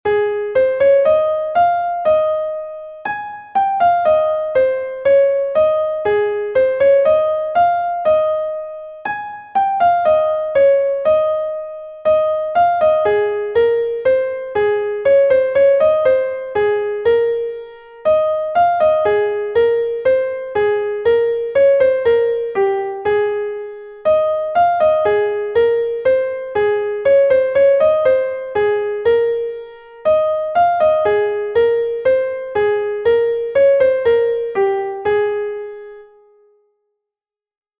Hanter dro Pays de Vannes I est un Hanter dro de Bretagne enregistré 2 fois par Evit Koroll de la Kevrenn de Rennes